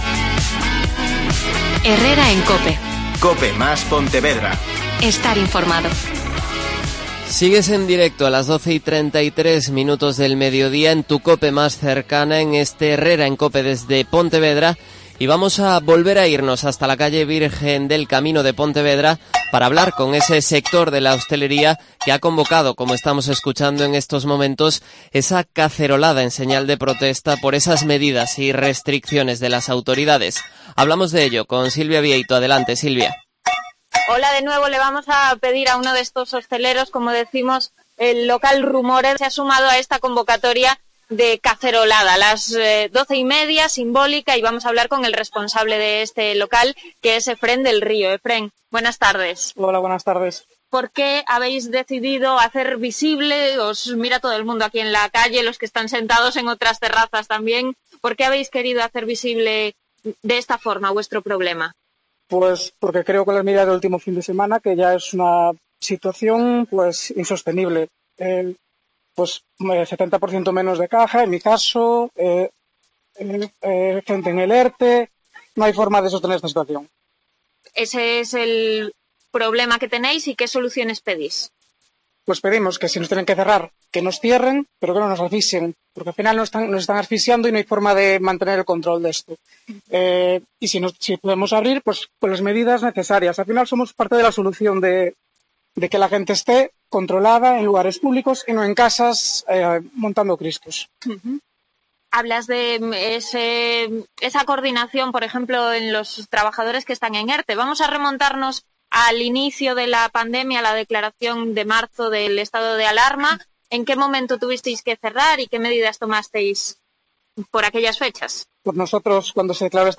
Conexión en directo con un local de la calle Virgen Peregrina en Pontevedra durante la cacerolada